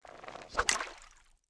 throw.wav